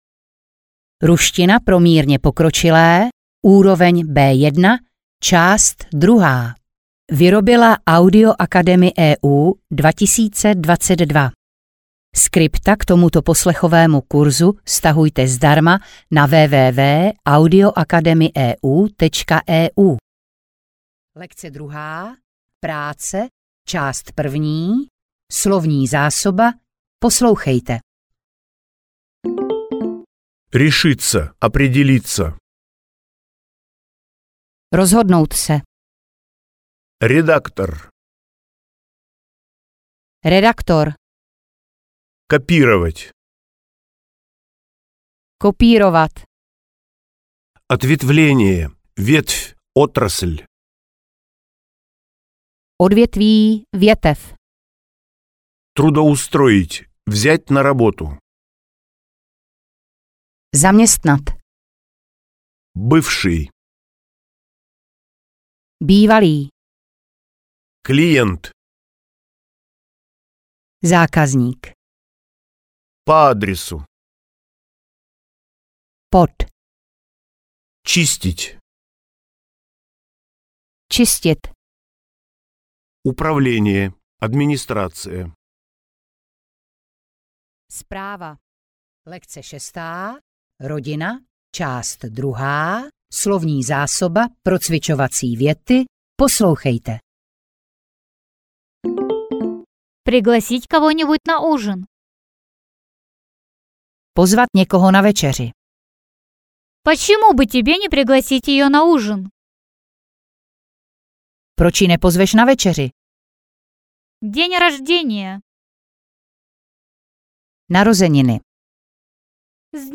Ruština pro mírně pokročilé B1 – část 2 audiokniha
Ukázka z knihy